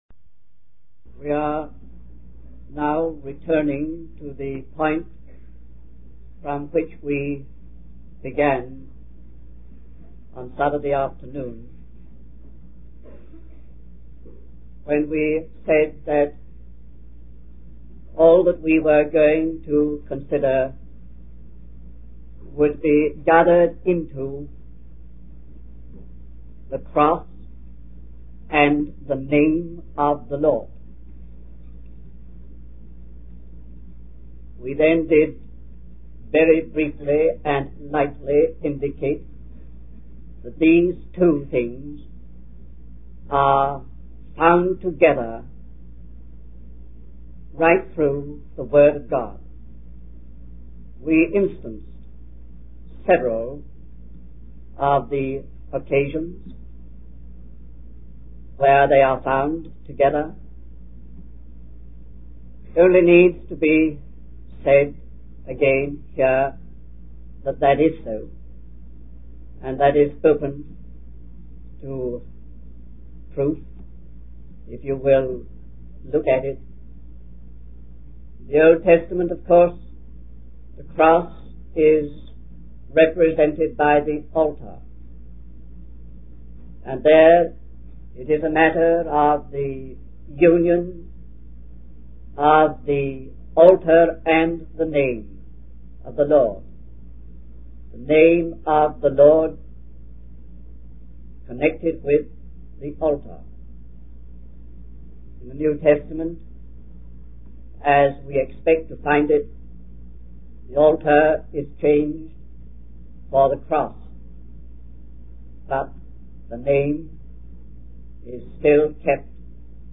In this sermon, the preacher explains the objective nature and meaning of the preaching of the word of God. He emphasizes that when a person truly understands and accepts the value of Christ's death and resurrection, they experience a sense of closeness and fellowship with God.